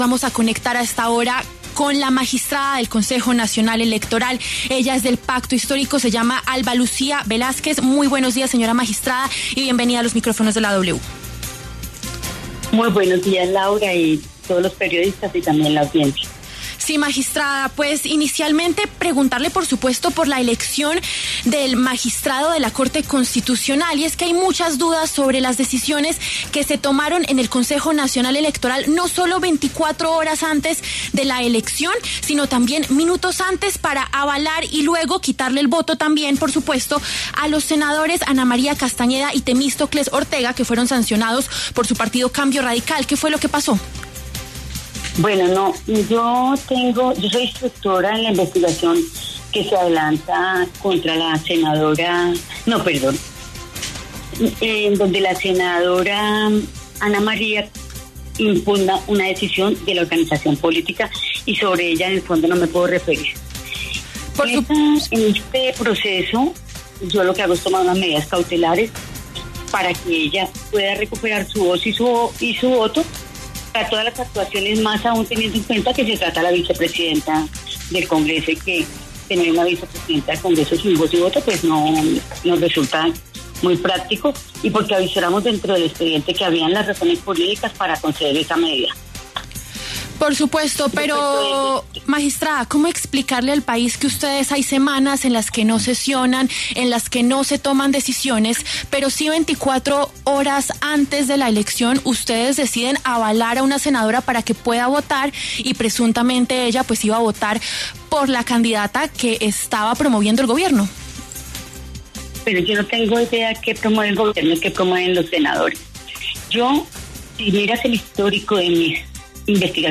Ante las críticas por la medida que salió del despacho de las magistradas, Alba Lucía Velásquez habló en los micrófonos de La W y se defendió. Aseguró que actuó bajo criterios jurídicos y no políticos.